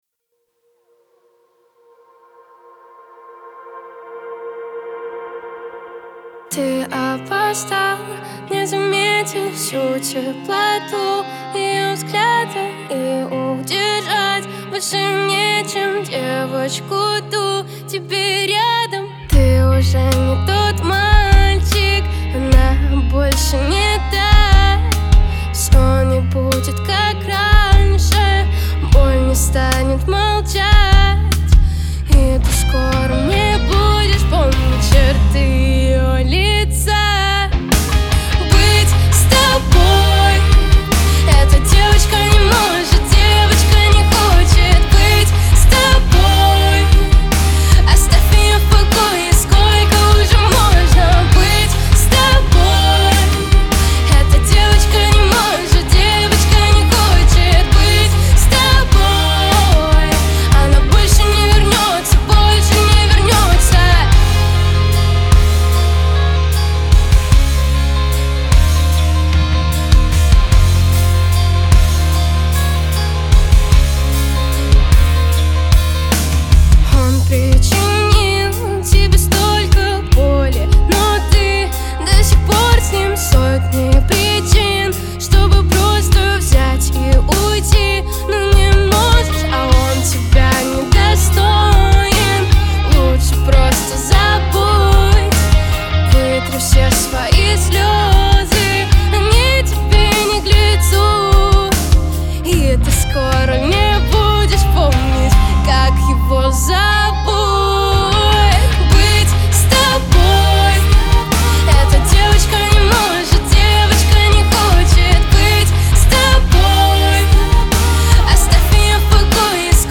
это проникновенный поп-трек с элементами R&B